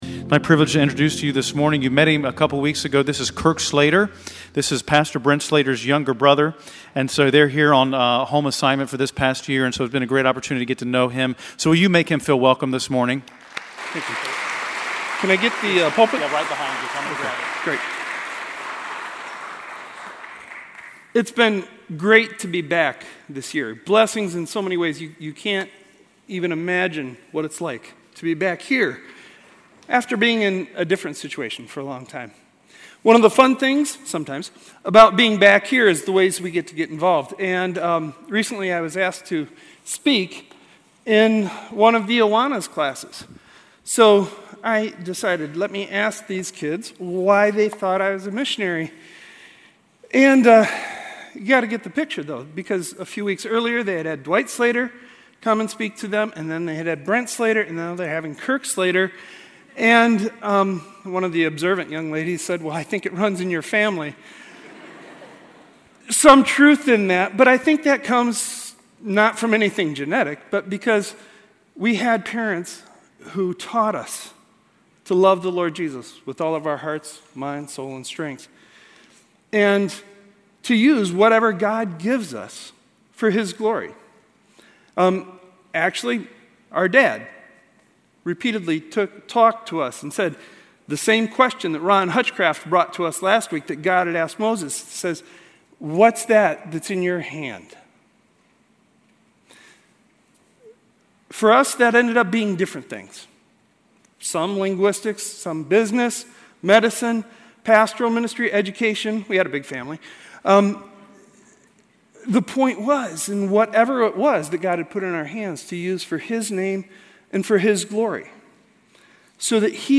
Miscellaneous Topics: Stand Alone Sermons « Our Unfinished Task A Portrait of Faith